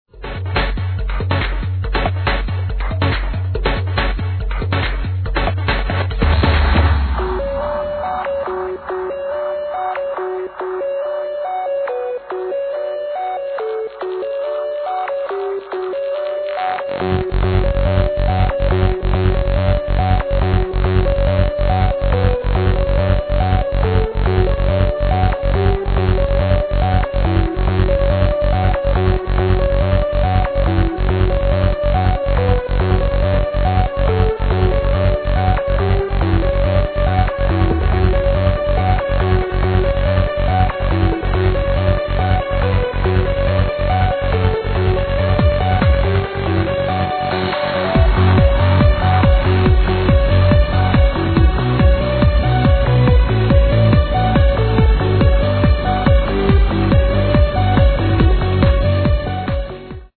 From the same Sydney set